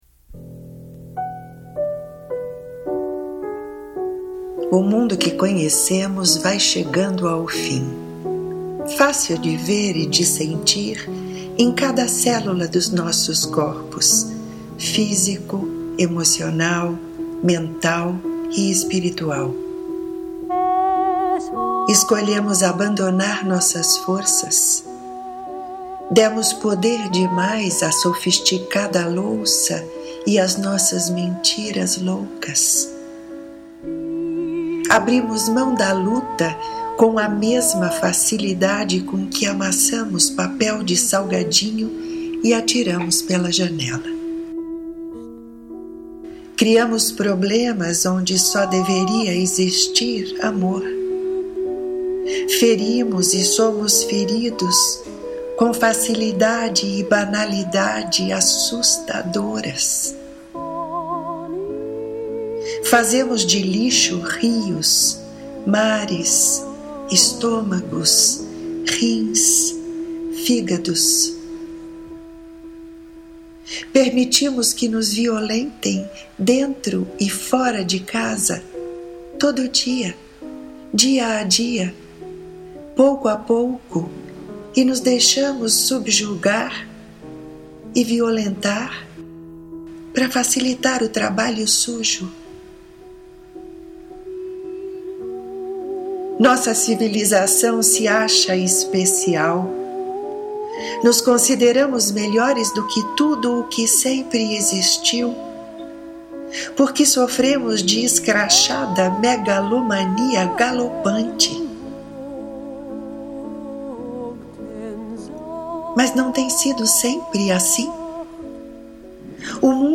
Ouça De Fim do Mundo na voz e sonorizado pela autora